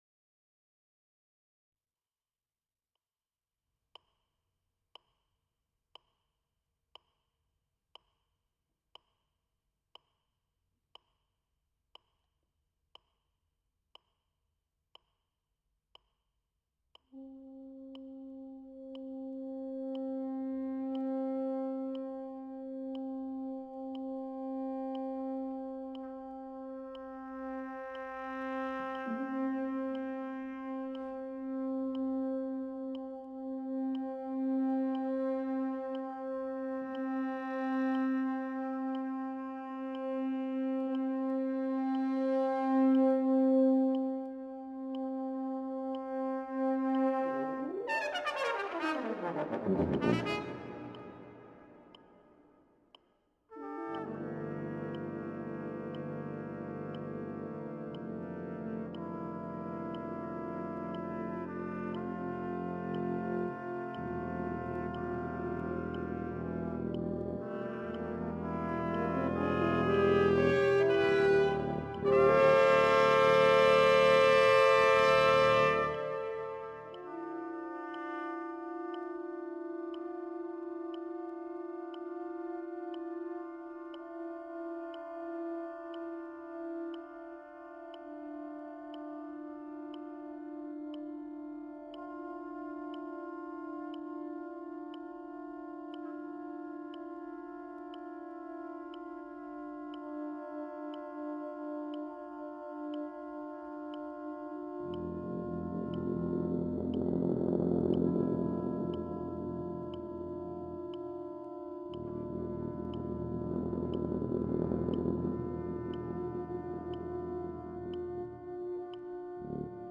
eine Suite für Blechbläserquintett